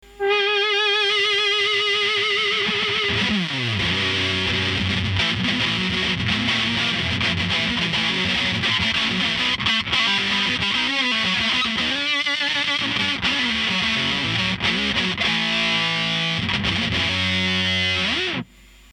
現行USA BIG MUFFとロシアBIG MUFFの間に位置するような70年代BIG MUFFトーンを奏でます。
ELECTRO-HARMONIX / Little Big Muff Piは、名器Big Mufをコンパクトなサイズで実現したディストーション/ファズ。